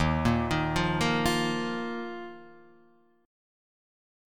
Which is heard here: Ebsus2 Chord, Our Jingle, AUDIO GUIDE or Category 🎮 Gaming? Ebsus2 Chord